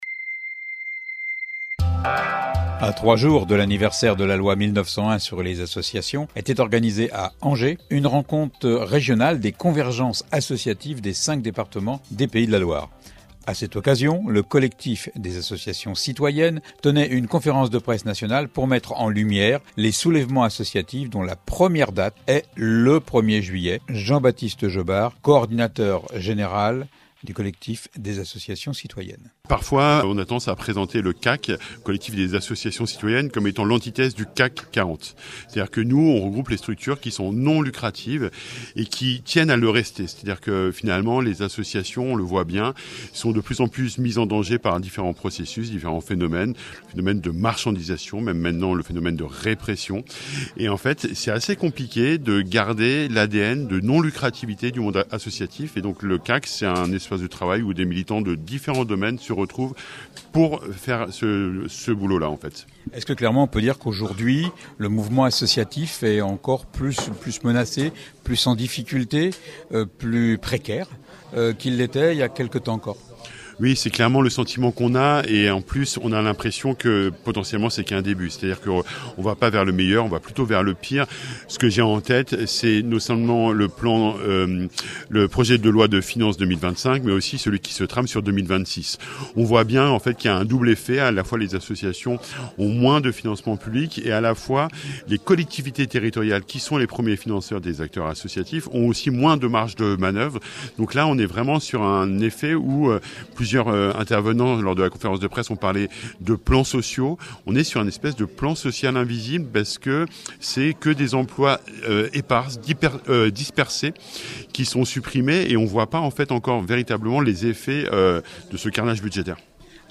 interviewé en 2011 à Rennes, à l’occasion d’une conférence-débat tenue aux Champs Libres.